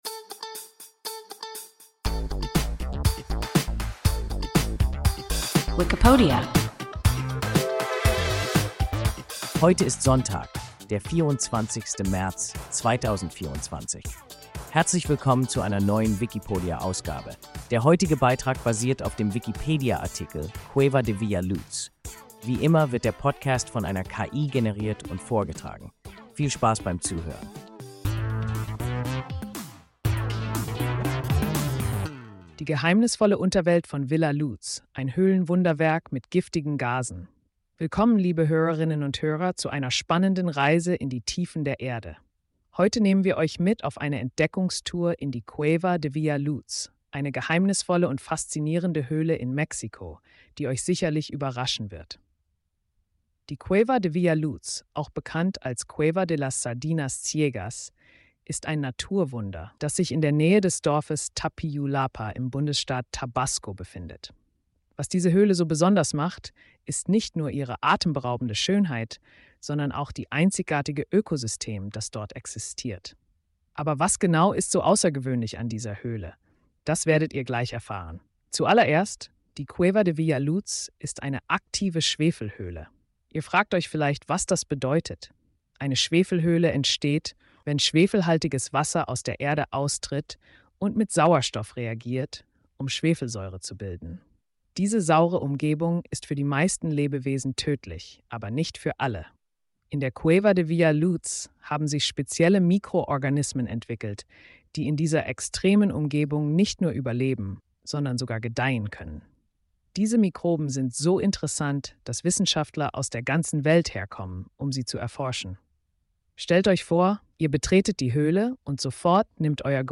Cueva de Villa Luz – WIKIPODIA – ein KI Podcast